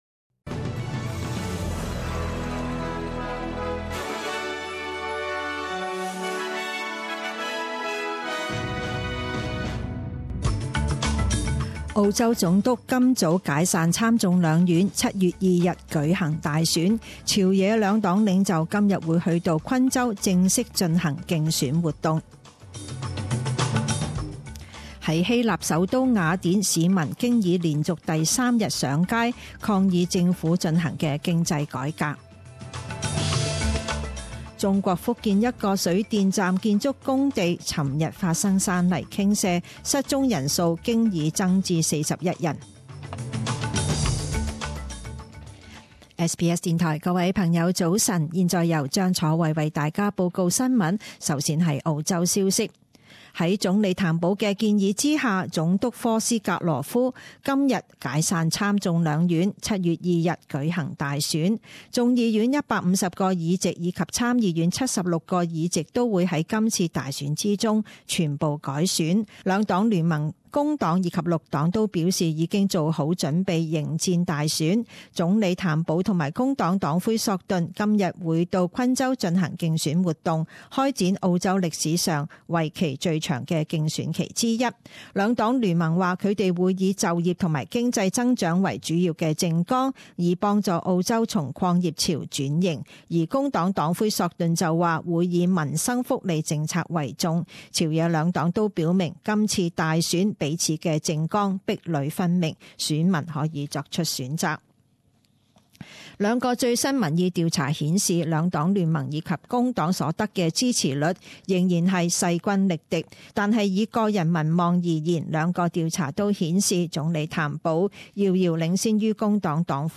Morning news bulletin